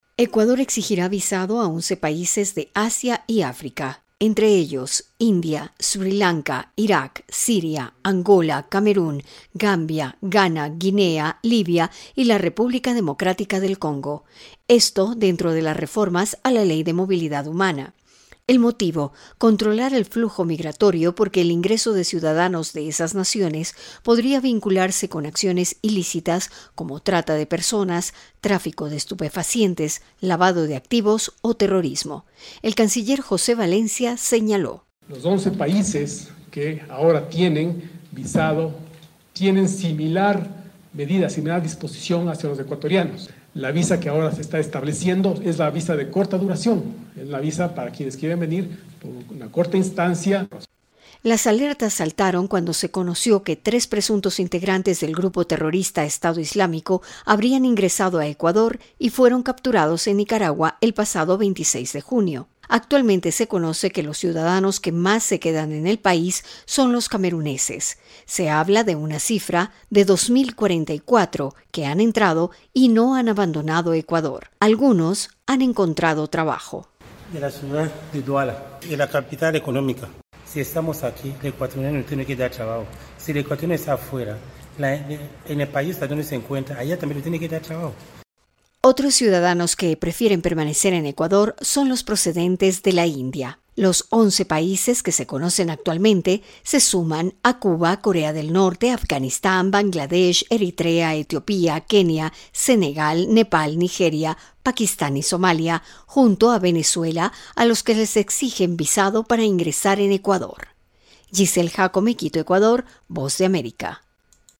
VOA: Informe desde Ecuador